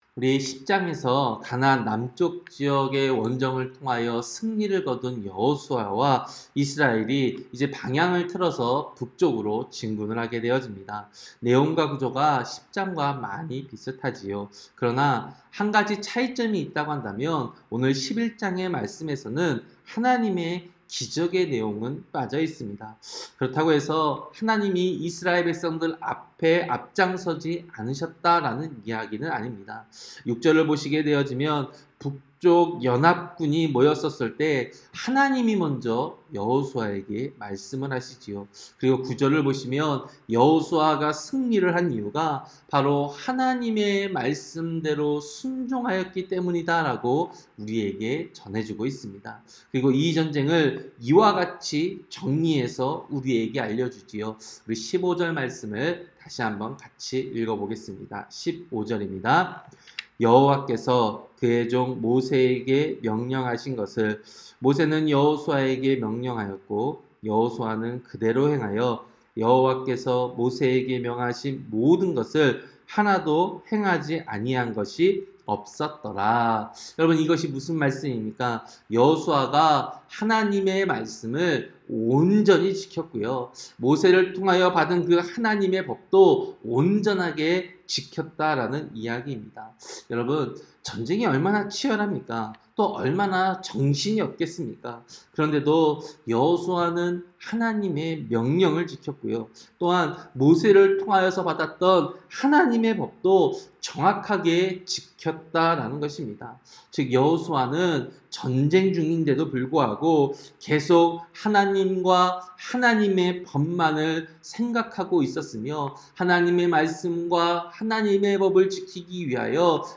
새벽설교-여호수아 11장